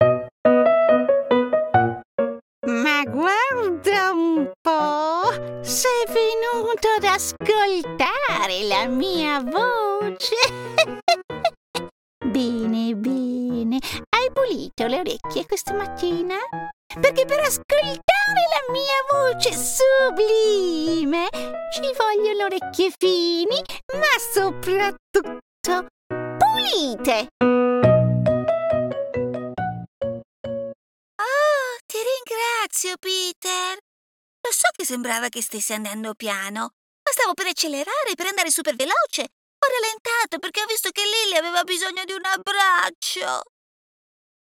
Character Showreel
Female
Italian
English with International Accent
Bright
Confident
Smooth
Soft